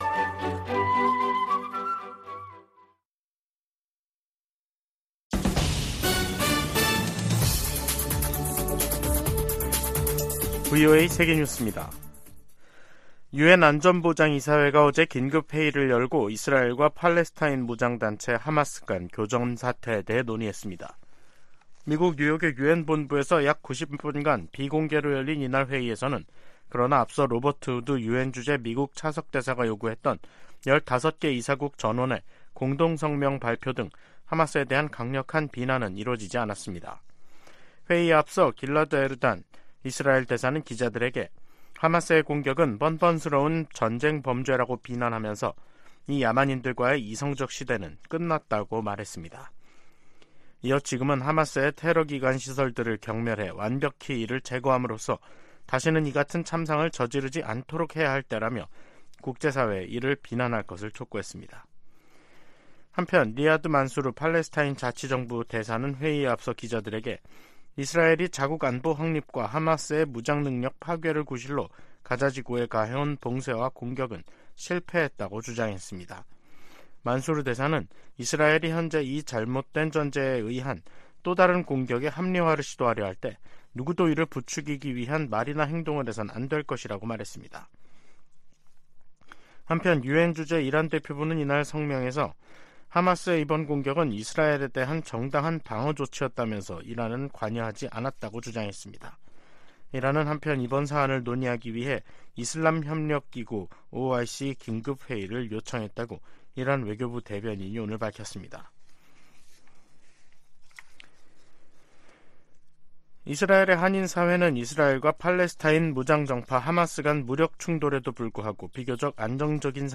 VOA 한국어 간판 뉴스 프로그램 '뉴스 투데이', 2023년 10월 9일 2부 방송입니다. 토니 블링컨 미 국무장관은 최근 한국에서 동결 해제된 이란 자금이 이스라엘 공격에 지원됐다는 일각의 주장을 일축했습니다. 한국에선 동북아 안보에 관한 미국의 집중도 하락, 북한이 중동의 전황을 반미연대 확대로 활용할 가능성 등이 제기되고 있습니다. 유럽연합(EU)은 러시아로 북한의 대포가 이전되기 시작했다는 보도에 관해 양국 무기 거래 중단을 촉구했습니다.